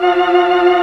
Index of /90_sSampleCDs/Giga Samples Collection/Organ/Barton Melo 16+8